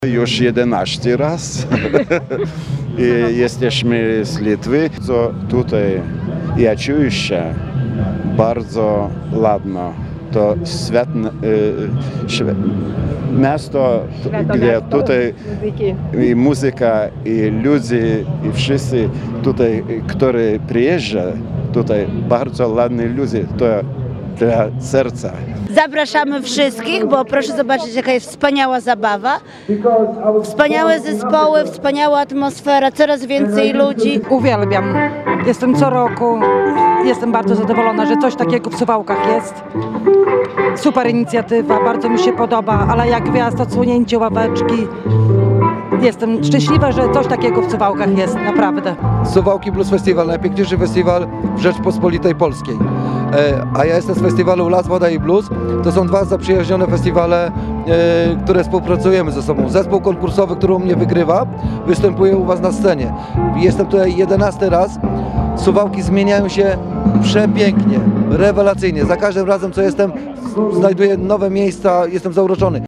– Jesteśmy stolicą bluesa nie tylko w Polsce, ale i w Europie – mówił Czesław Renkiewicz, prezydent Suwałk.
– Takiego klimatu, jak w Suwałkach, nie ma nigdzie indziej – mówili zapytani przez nas turyści.